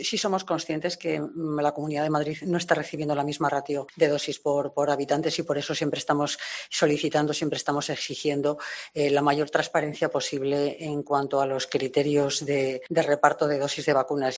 Elena Andradas, directora de Salud Pública, explicando que llegan pocas vacunas a Madrid